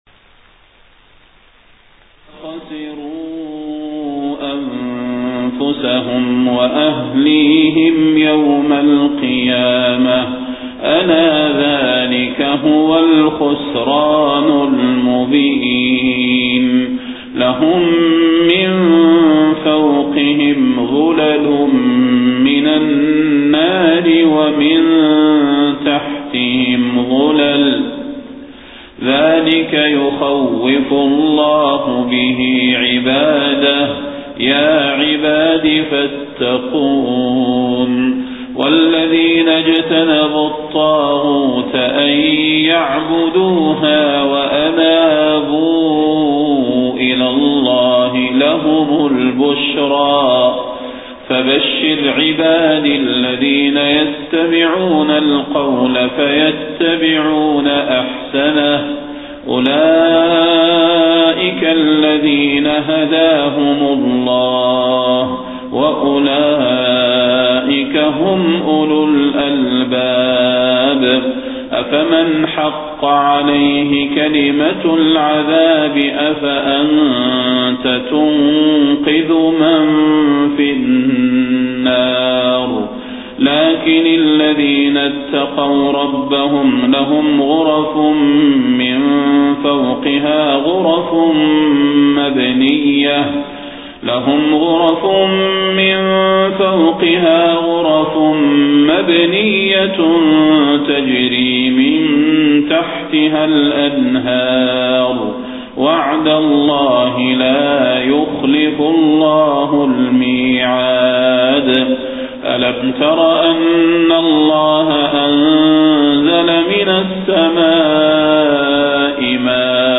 صلاة الفجر 25 صفر 1431هـ من سورة الزمر 15-31 > 1431 🕌 > الفروض - تلاوات الحرمين